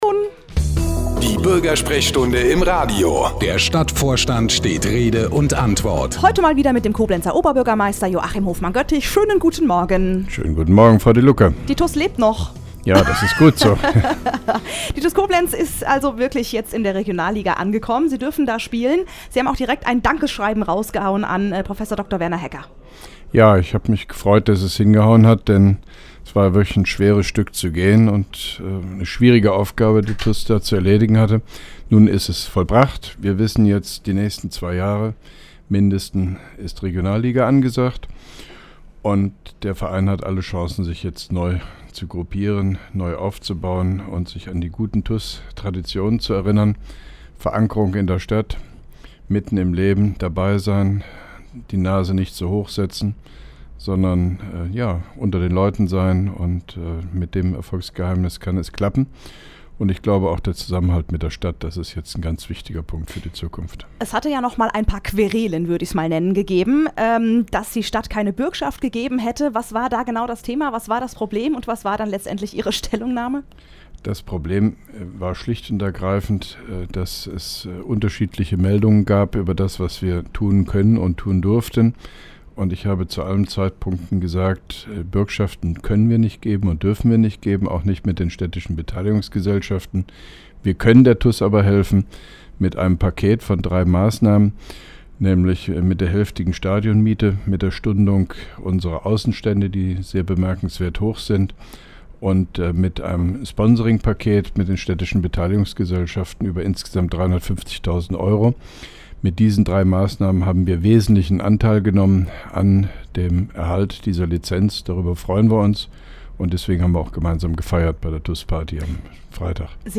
(1) Koblenzer Radio-Bürgersprechstunde mit OB Hofmann-Göttig 05.07.2011
Interviews/Gespräche